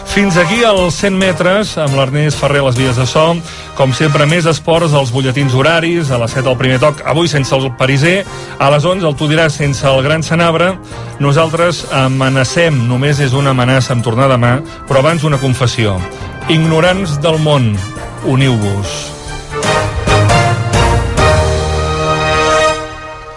Comiat del programa el dia de la ràdio cap per avall amb motiu del dia mundial de la ràdio
Esportiu
FM